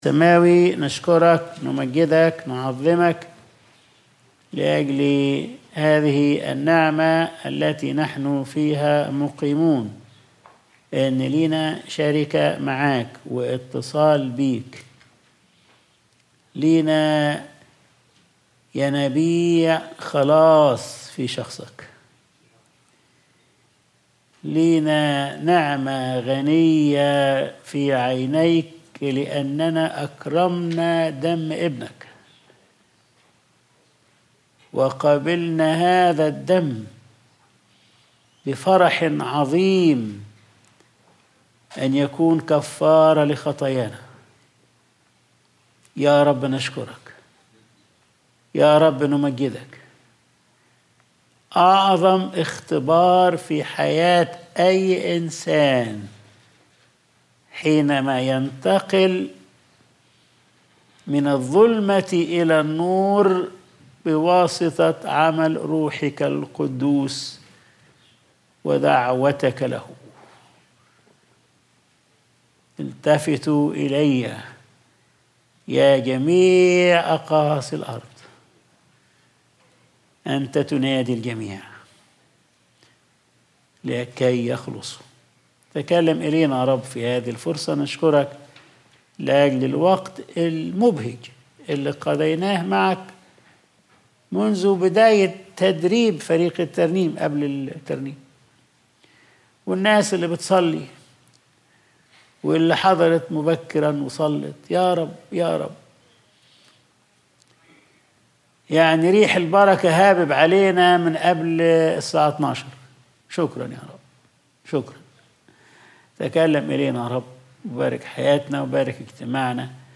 Sunday Service | لا يغادر شيء صاحبه